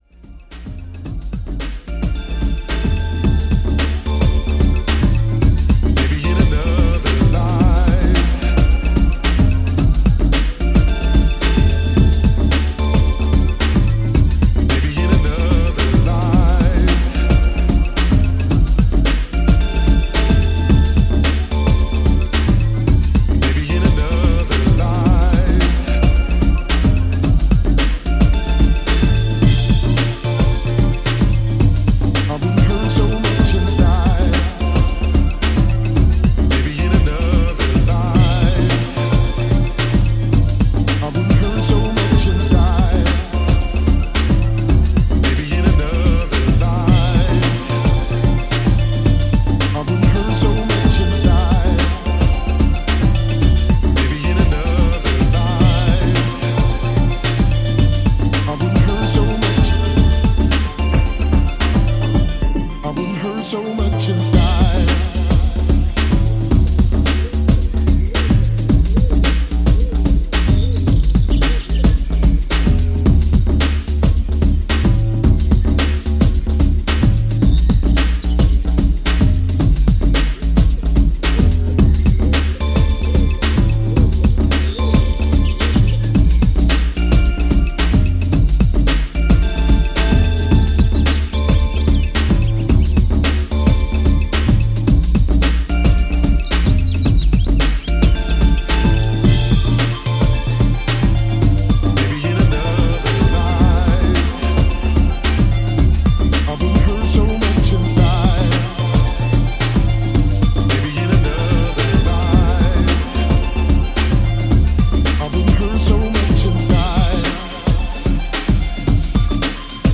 classic rave sounds